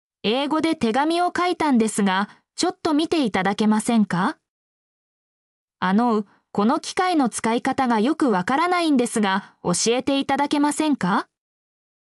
mp3-output-ttsfreedotcom_I6hUvUIZ.mp3